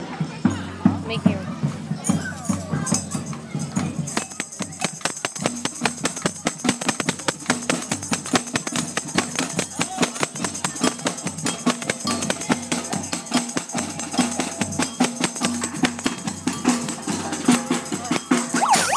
Bull City Strutters at the Hillsborough, NC "handmade parade"